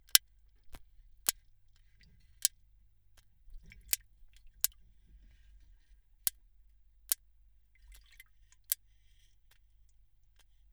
Sons de estalidos altos foram descritos num estudo publicado esta quarta-feira na Royal Society Open Science.
Quando suavemente manuseados, cada tubarão produziu, em 20 segundos, cliques curtos e de alta frequência, com uma duração média de 48 milissegundos e frequências de pico que variavam entre 2,4 e 18,5 kilohertz.